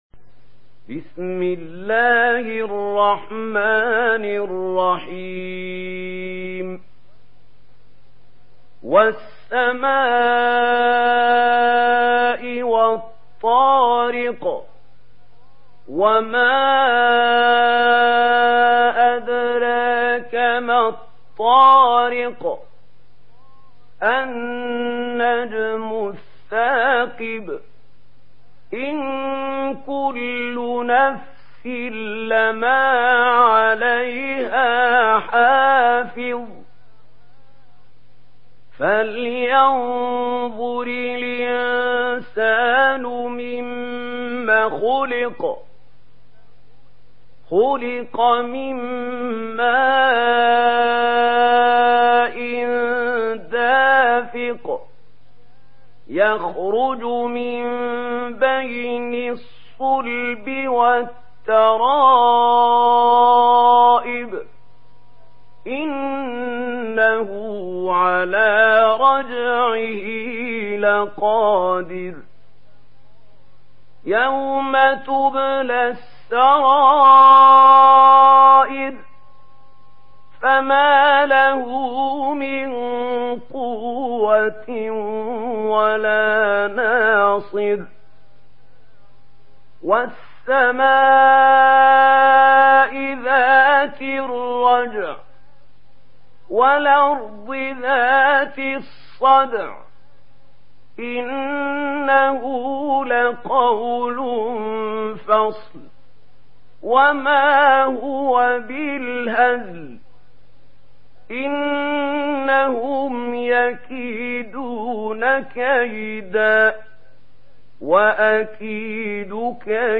Sourate At-Tariq MP3 à la voix de Mahmoud Khalil Al-Hussary par la narration Warsh
Une récitation touchante et belle des versets coraniques par la narration Warsh An Nafi.
Murattal Warsh An Nafi